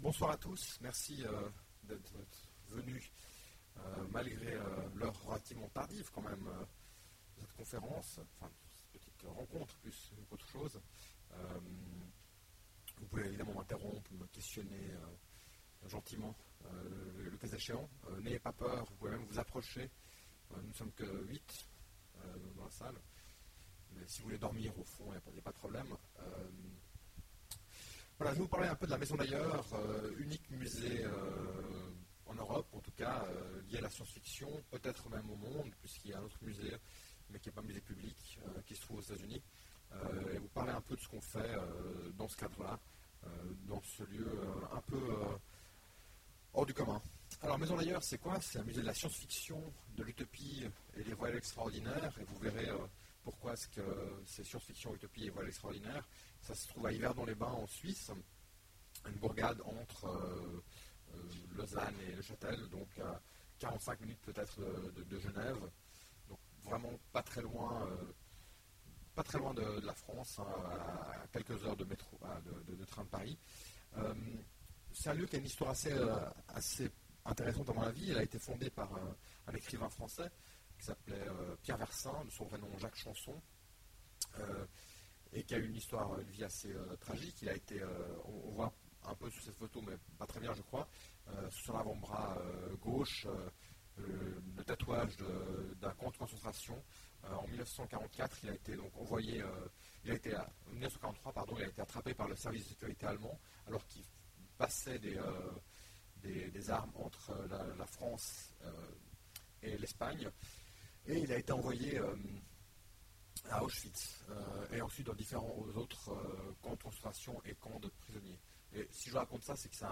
Conférence : La Maison d’Ailleurs
Voici l'enregistrement des 45 premières minutes de la conférence autour de la Maison d'Ailleurs lors des rencontres de l'Imaginaire de Sèvres du 12 décembre 2009. La fin de la conférence nous a malheureusement échappé faute de batterie sur l'enregistreur...